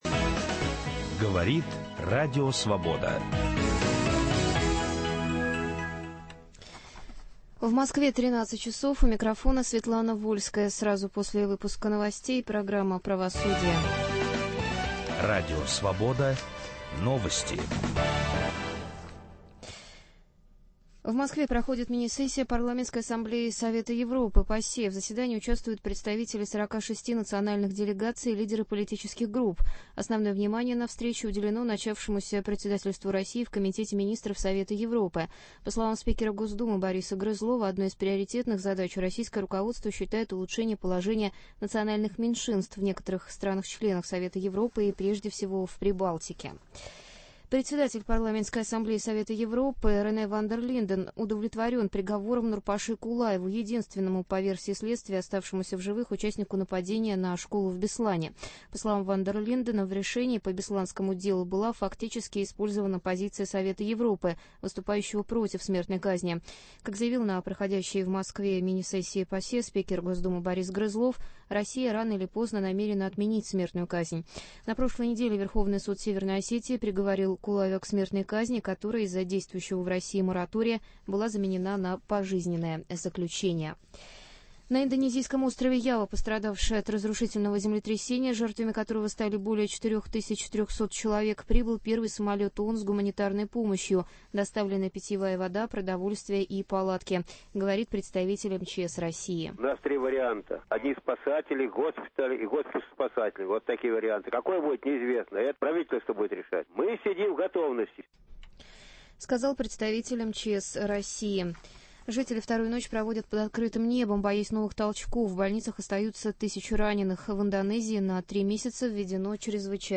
Нуждается ли Россия в принятии закона, запрещающего смертную казнь? На этот вопрос отвечают эксперты в студии Радио Свобода – судья Конституционного суда в отставке, доктор юридических наук Тамара Морщакова и прокурор в отставке, кандидат юридических наук Юрий Синельщиков.